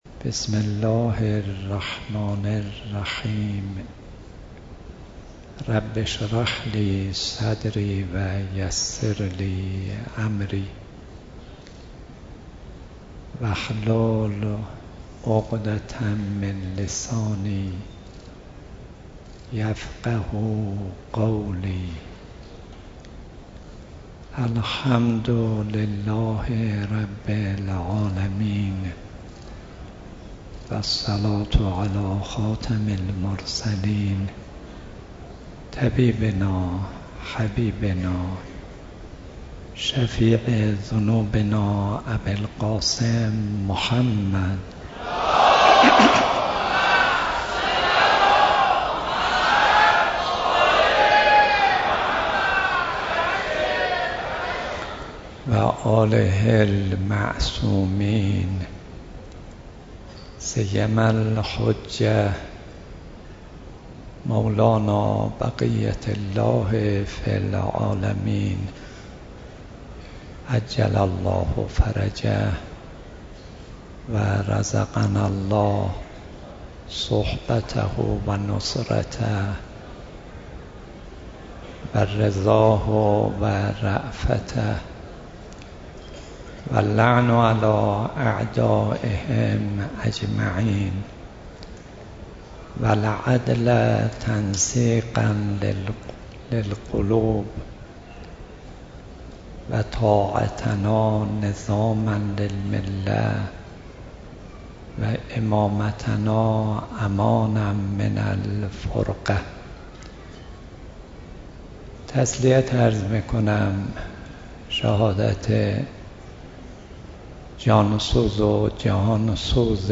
اولین شب مراسم عزاداری شهادت حضرت فاطمه‌ زهرا (سلام‌الله‌علیها) با حضور رهبر معظم انقلاب اسلامی، رؤسای قوای مقننه و قضائیه، جمعی از مسئولان و قشرهای مختلف مردم و با سخنرانی حجت‌الاسلام صدیقی در حسینیه‌ی امام خمینی (ره) برگزار شد.
سخنرانی حجت‌الاسلام صدیقی